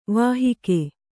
♪ vāhike